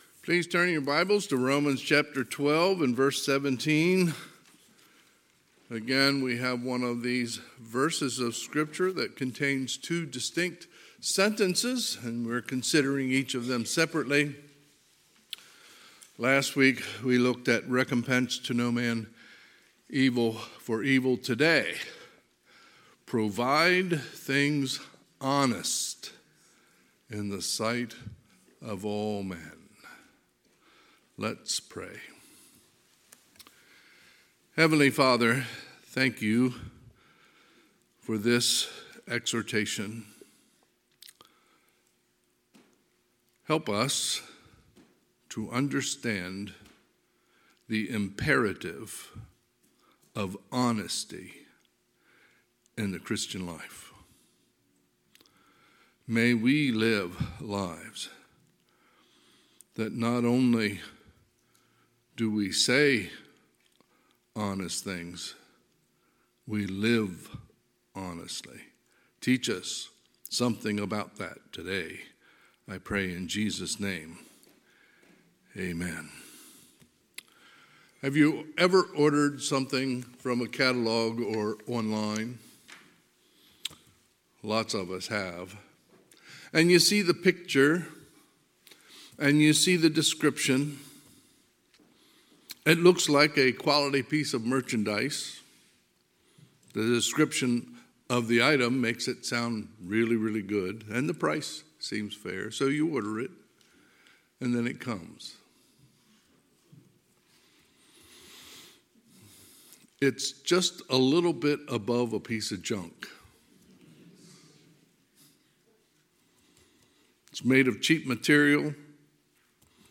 Sunday, October 9, 2022 – Sunday AM